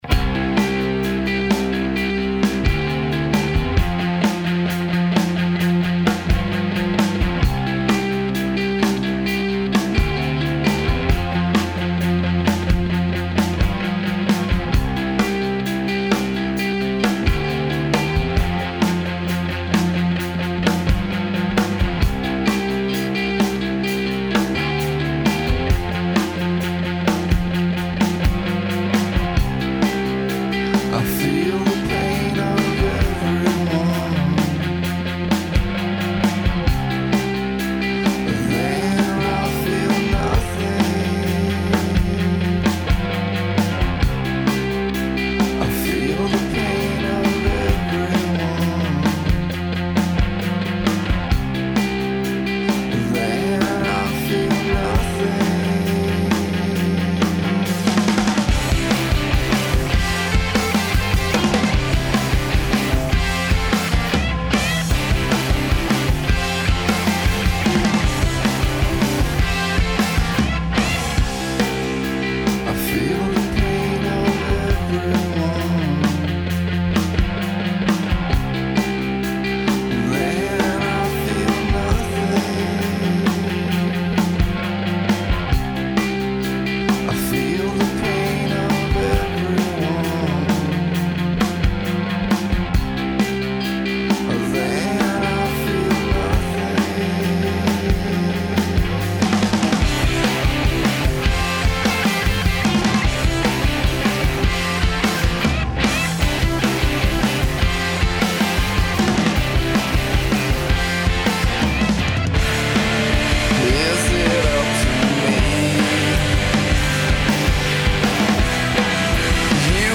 Especially, when it comes to the guitar noodling.